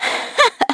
Xerah-Vox-Laugh.wav